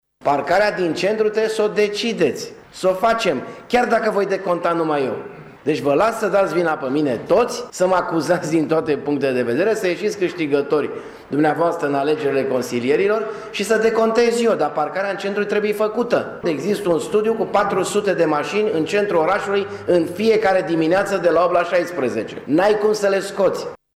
Pentru a rezolva problema locurilor de parcare, primarul Dorin Florea, prezent la ședință, a cerut consilierilor să voteze cât mai repede proictut privind construirea unei parcări subterane în centrul orașului: